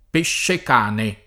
peššek#ne] s. m.; pl. pescicani o pescecani — nel sign. proprio («squalo»), anche (antiq.) pesce cane [id.]; pl. pesci cani — nel sign. fig. spreg. di «profittatore, speculatore; uomo rapace e senza scrupoli», in uso dai primi del ’900, sempre in gf. unita; così pure nel femm. pescecagna [